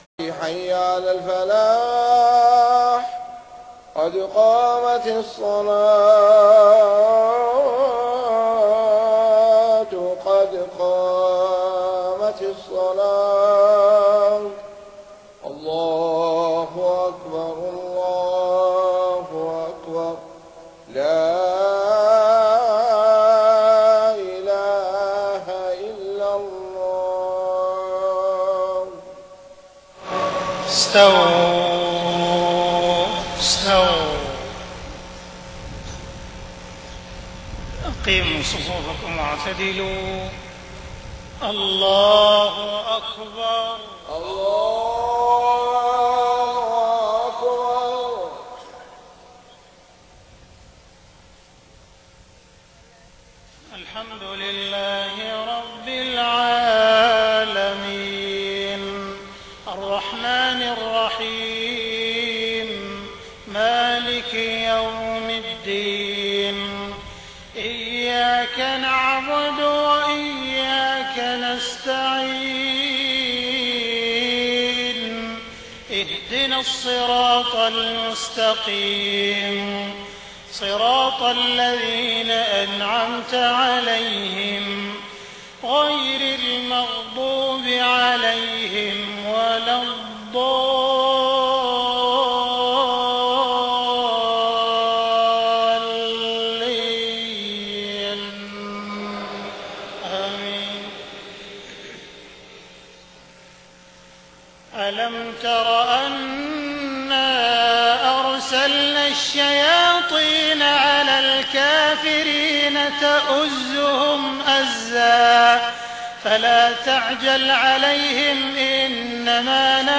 صلاة المغرب 26 محرم 1430هـ خواتيم سورة مريم 83-98 > 1430 🕋 > الفروض - تلاوات الحرمين